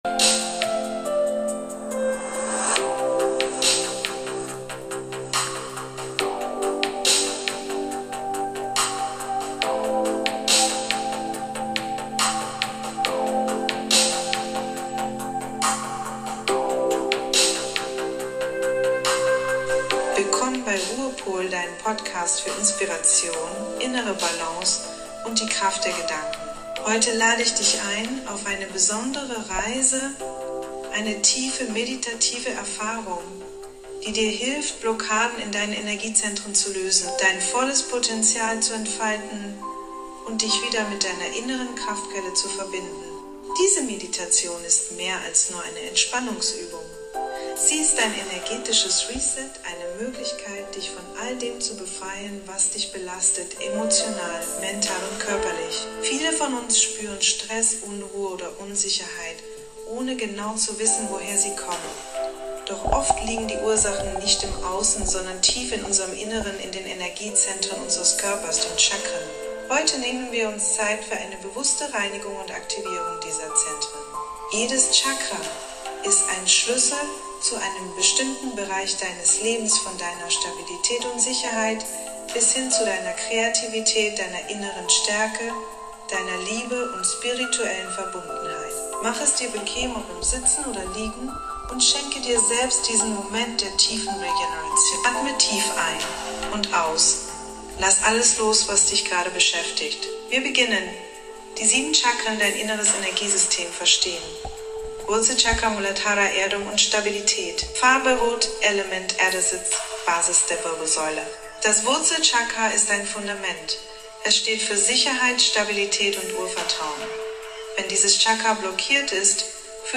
Chakra Meditation – Energiezentren harmonisieren & Blockaden